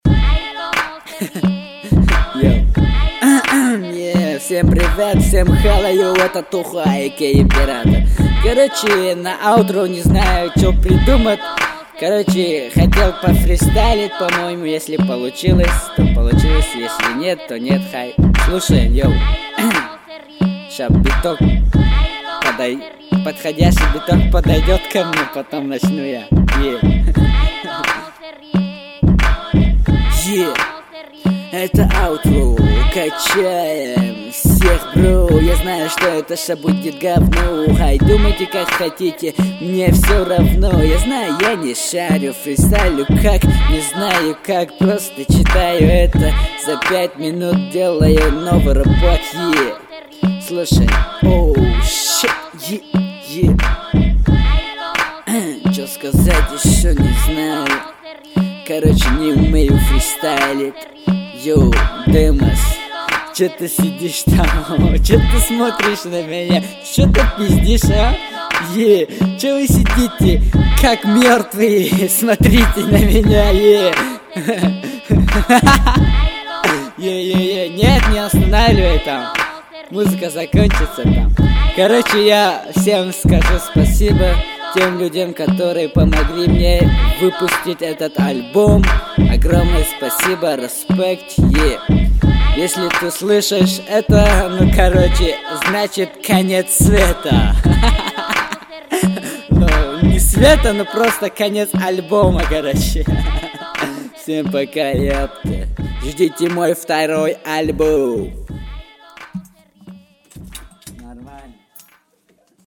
Главная » Каталог mp3 » Рэп / HIP HOP » Tajik Rap